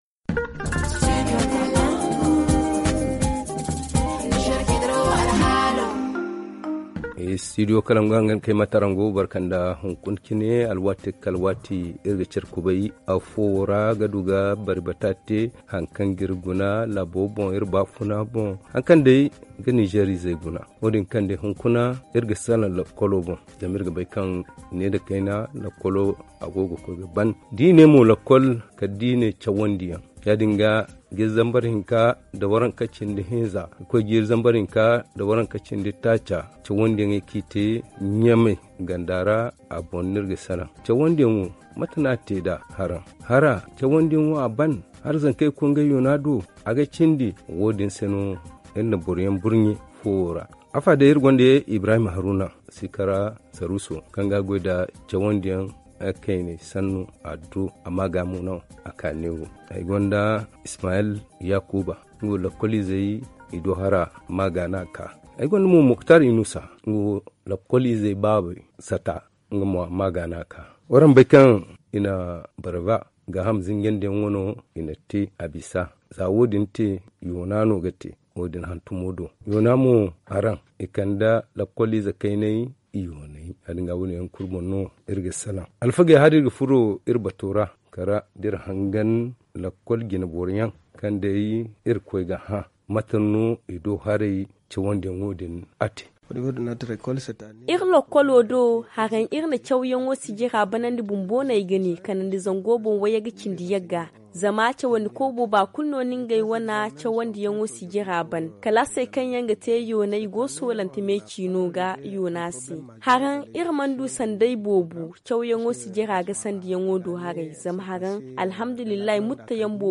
ZA Le forum en zarma Télécharger le forum ici.